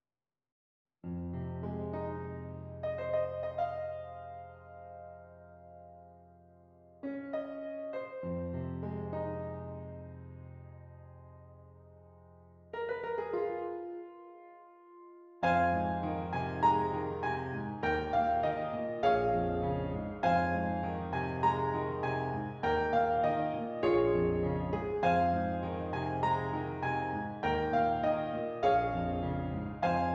Does Not Contain Lyrics
F Minor
Moderately